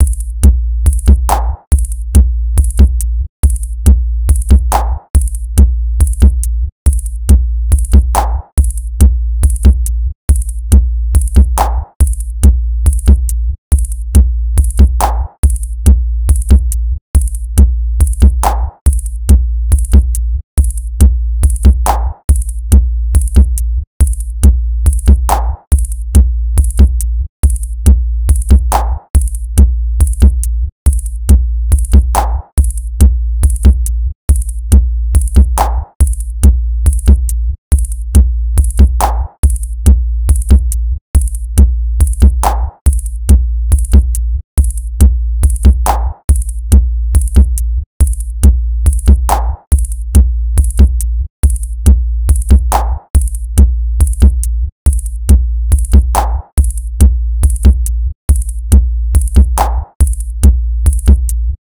simple drumbeat
A very simple drubeat.
drumbeat_body_ogg.ogg